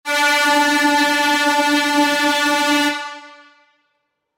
Alarme Sonoro Industrial Quadrante Modelo 1
Tem como característica o som de baixa frequência e o longo alcance além de atender as exigências legais das áreas de segurança do trabalho e/ou das brigadas de incêndio das empresas.
• 04 Cornetas em plástico de engenharia;
• Intensidade sonora 110db/corneta;
• Som de baixa frequência e longo alcance;
• Sistema pneumático;